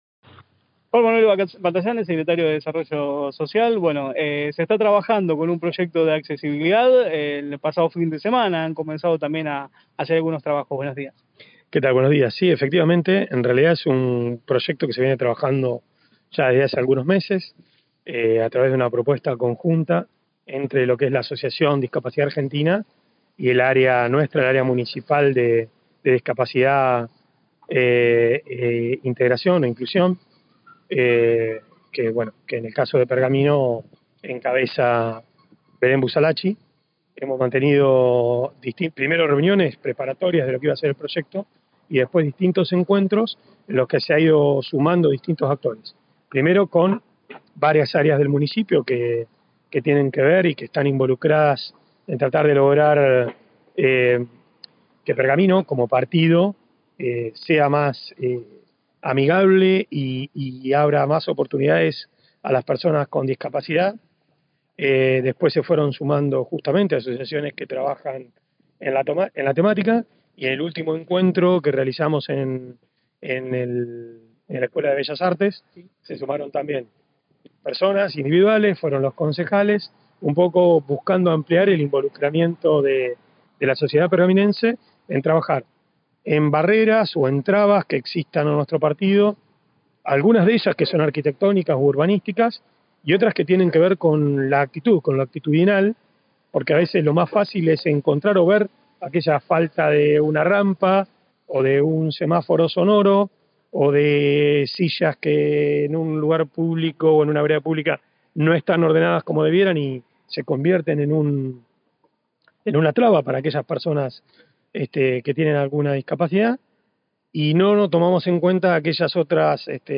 En el marco del programa La Mañana de la Radio de LT35 Radio Mon AM 1540, el móvil dialogó con el secretario de Desarrollo Social, Juan Manuel Batallanes, quien brindó detalles sobre el avance de un proyecto de accesibilidad que ya comenzó a desarrollarse en la ciudad de Pergamino.